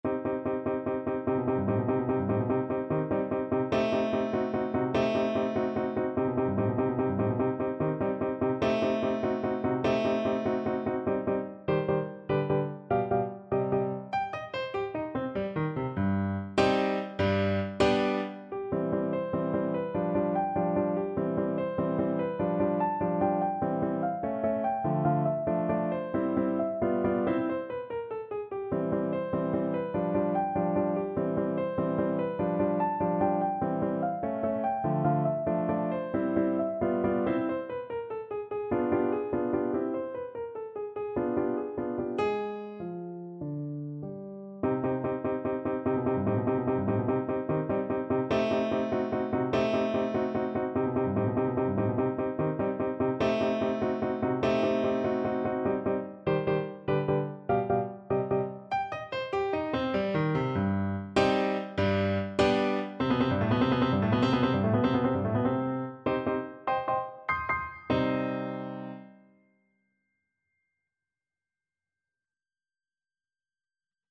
No parts available for this pieces as it is for solo piano.
3/8 (View more 3/8 Music)
Eb major (Sounding Pitch) (View more Eb major Music for Piano )
Allegro con brio (.=104) .=98 (View more music marked Allegro)
Piano  (View more Intermediate Piano Music)
Classical (View more Classical Piano Music)
burgmuller_op100_15_PNO.mp3